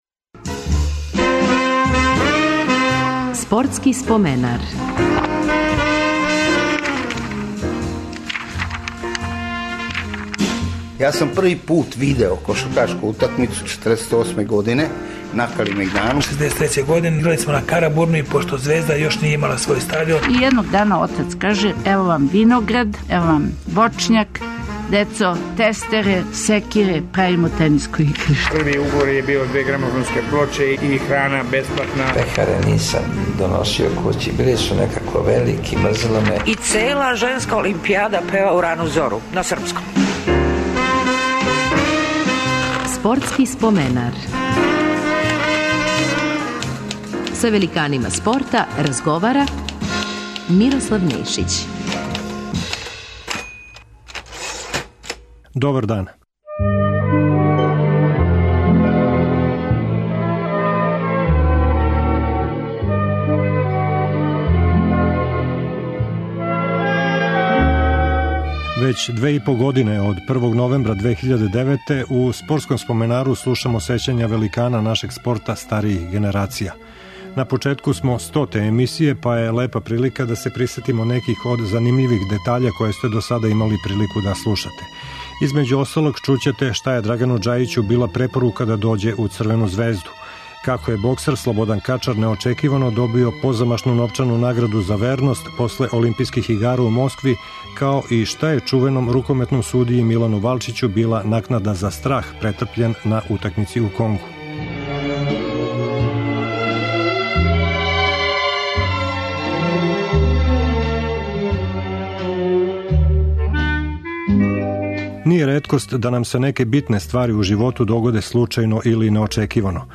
То је прилика да поново чујете неке од најзанимљвијих делова досадашњих издања Спортског споменара. Фрањо Михалић ће говорити због чега је готово читаву каријеру трчао у истим спринтерицама.
Драгослав Шекуларац ће говорити како је постао првотимац Црвене звезде, а Драган Џајић шта га је препоручило црвено-белима. Скакач у даљ Ненад Стекић ће описати како се осећао током скока од 8 метара и 45 сантиметара.